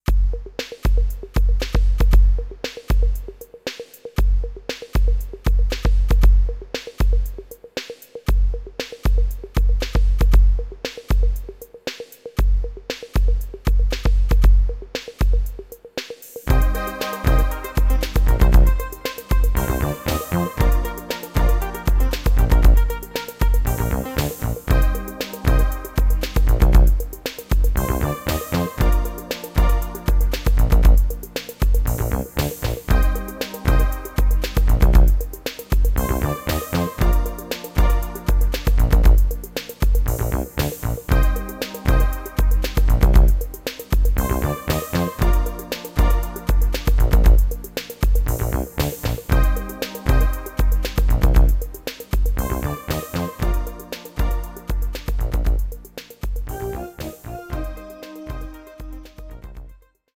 Echter Freestyle